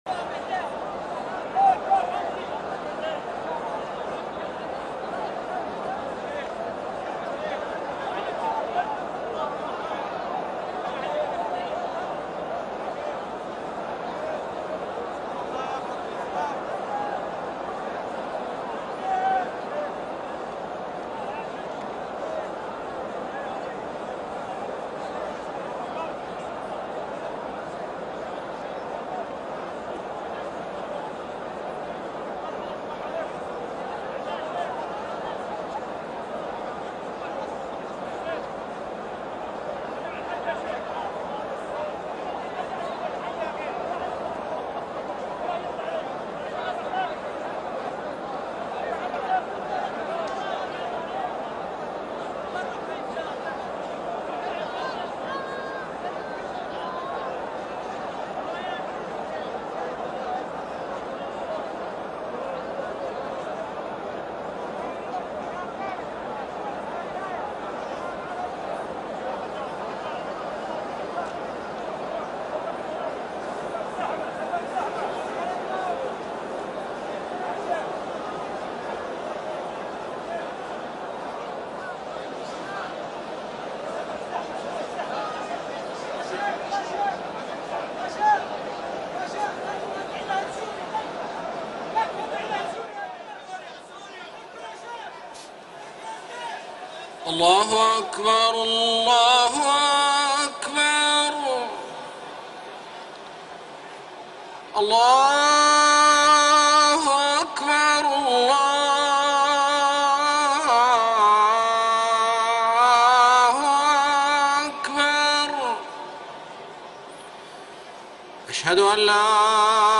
صلاة المغرب 12 ذو الحجة 1433هـ سورتي الكوثر والنصر > 1433 🕋 > الفروض - تلاوات الحرمين